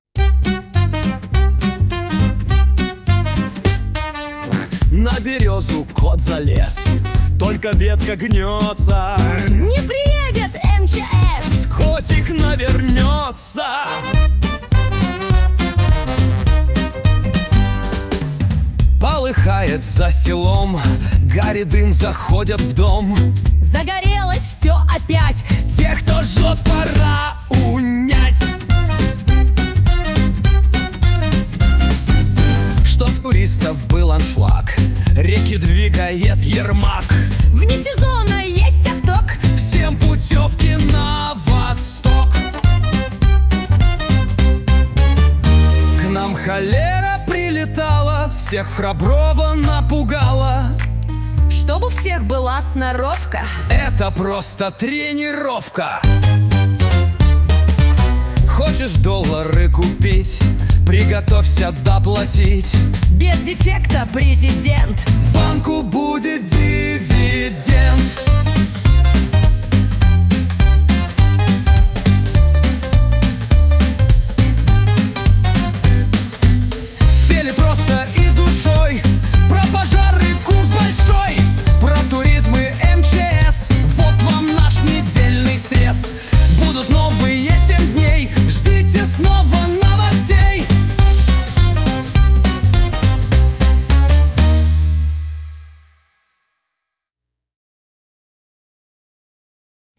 Самые заметные и важные события — в стиле куплетов